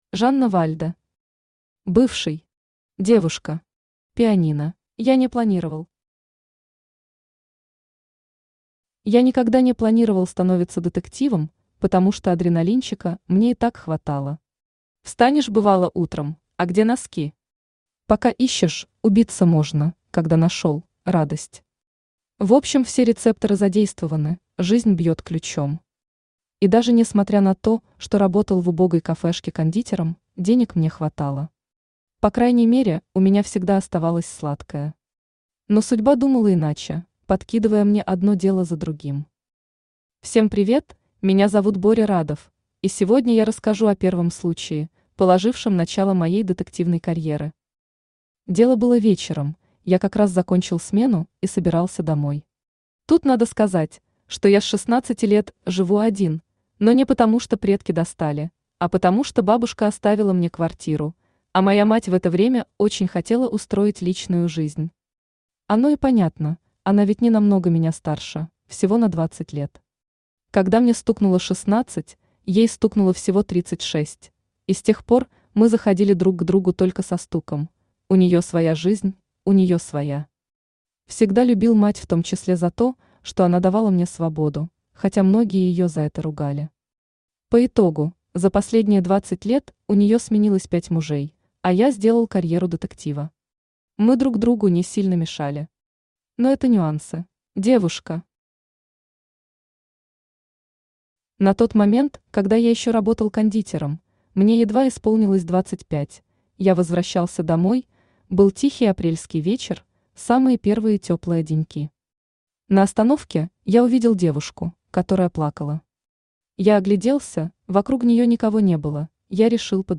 Аудиокнига Бывший. Девушка. Пианино | Библиотека аудиокниг
Пианино Автор Жанна Вальда Читает аудиокнигу Авточтец ЛитРес.